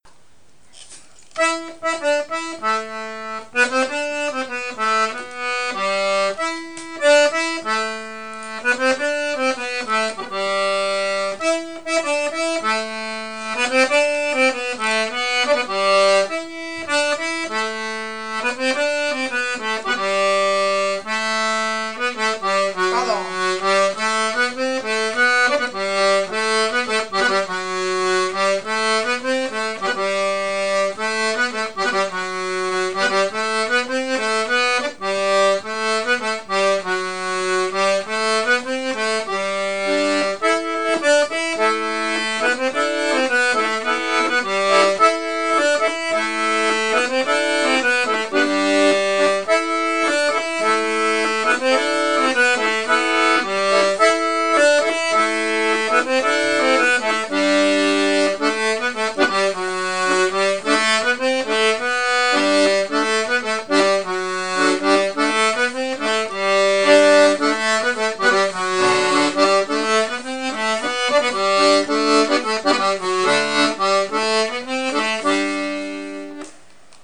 l'atelier d'accordéon diatonique
Bonus à découvrir: bourrée 2t la bergere de Coulandon
mélodie